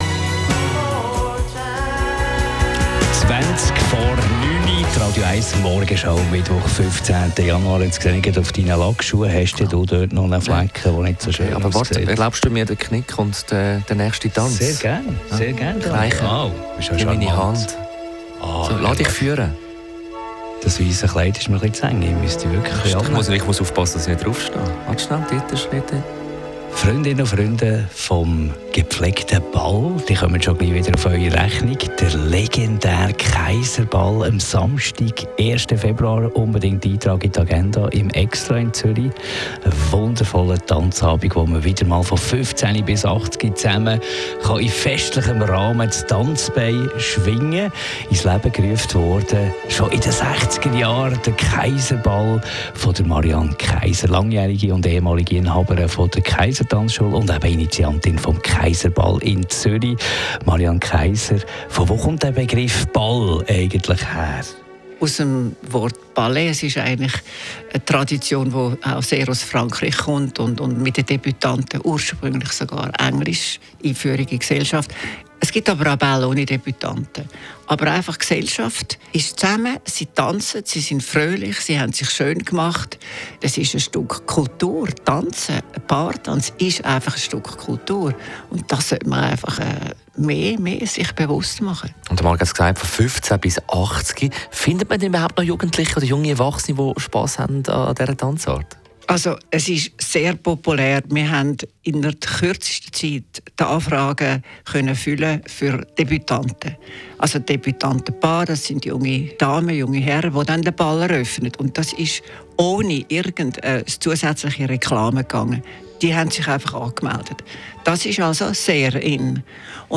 Radio 1 Morgenshow, Interview